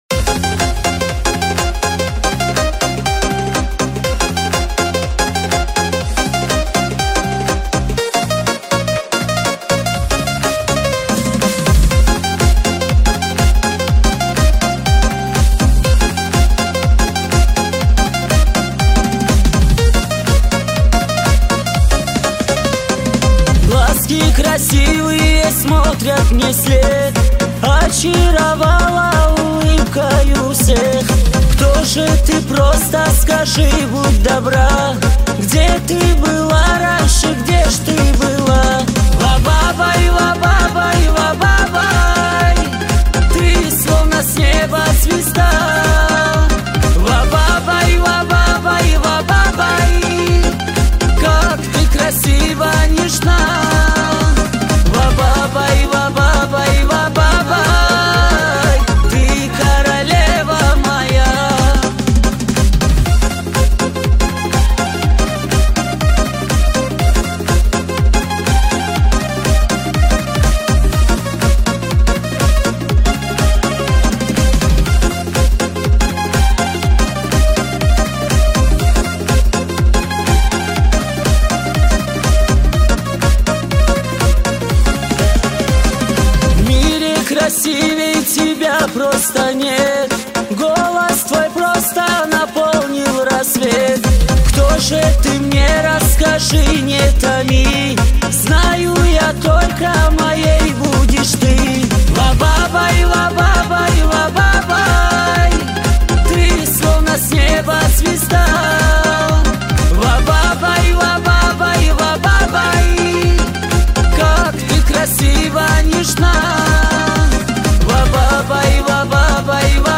Cover version Xit 2022 новинка на русском языке